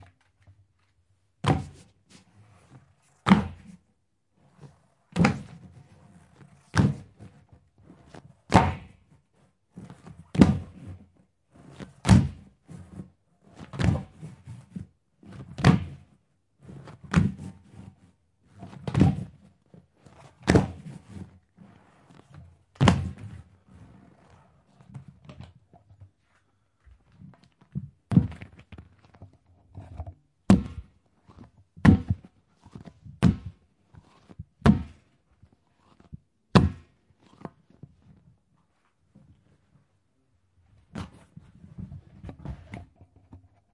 击球橡胶
描述：打在橡胶球上
Tag: 下降 命中 冲击 橡胶